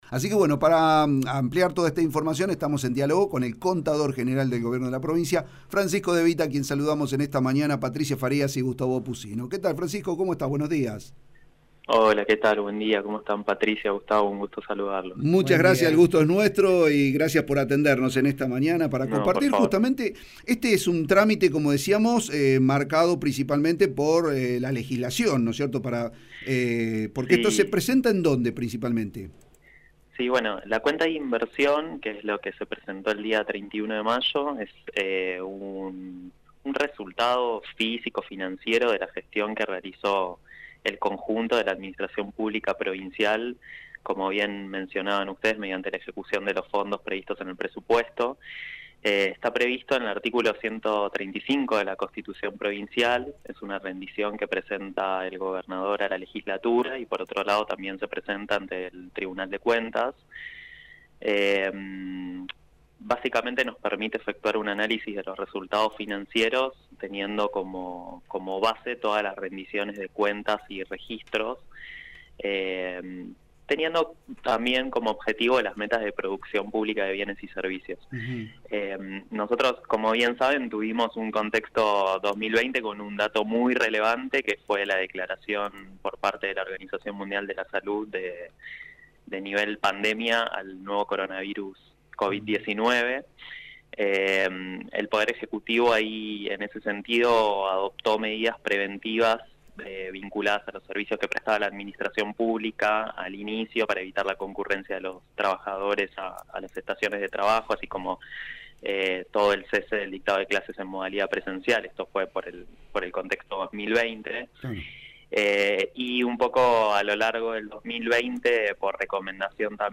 En diálogo con la FM 103.1, el Contador General de la Provincia, Francisco Devita, brindó detalles de la presentación de la rendición de gastos del año 2020 a la Legislatura fueguina y al Tribunal de Cuentas de la Provincia.